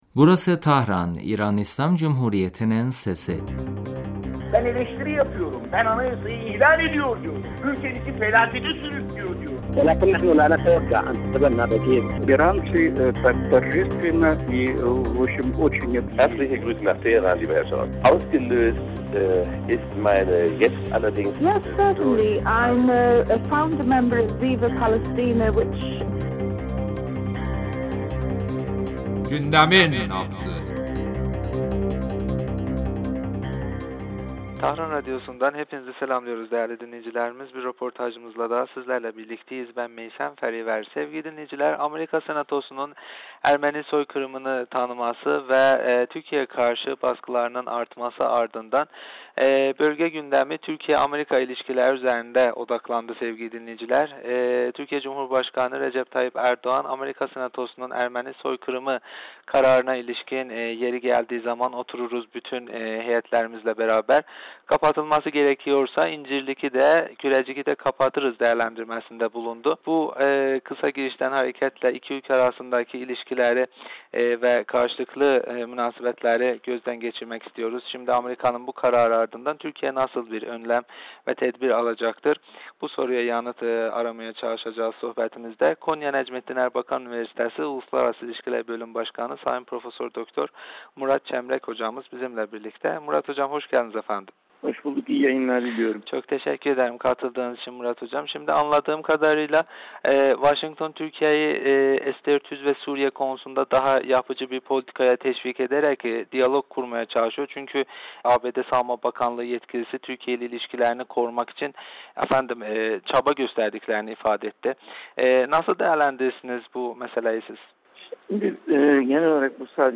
radyomuza verdiği demecinde Türkiye yetkililerinin ABD'nin skandal kararlarına ilişkin gösterdiği tepkileri ve iki ülke ilişkilerindeki son durumu değerlendirdi.